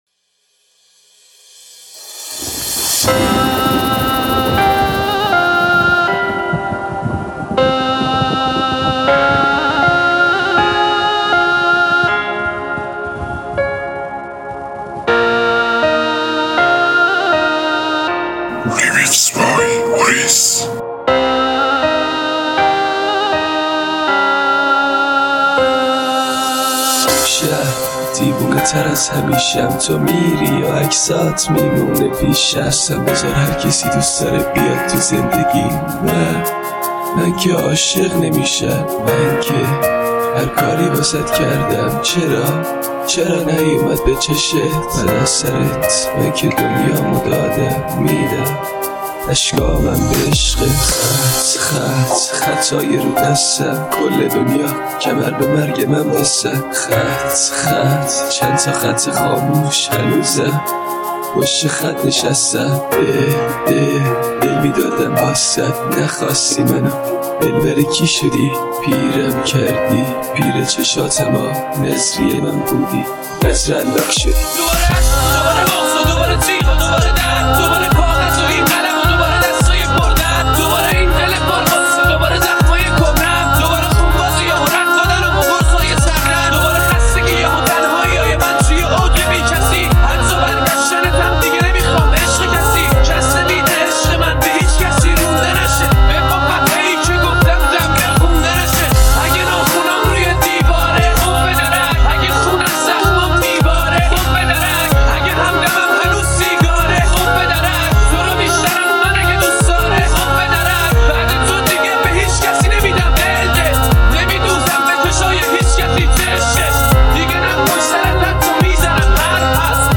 ریمیکس غمگین رپ آهنگ کردی غمگین